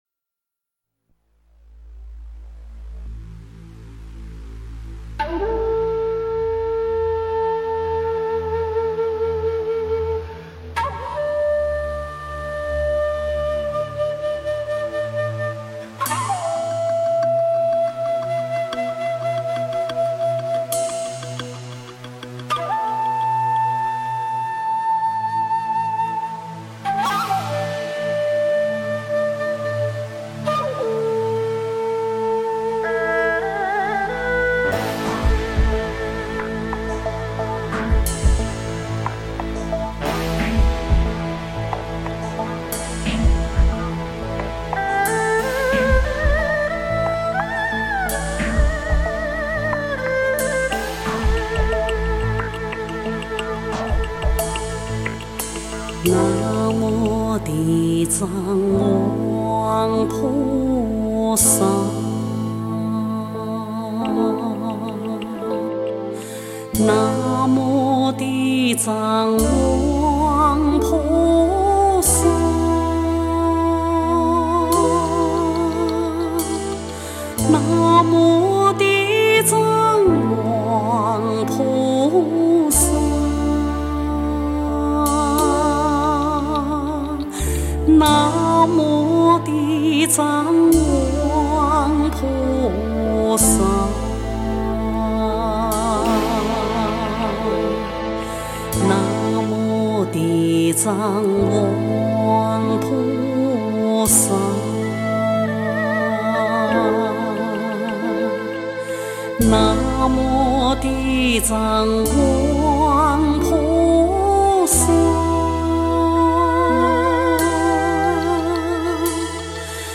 标签: 佛音经忏佛教音乐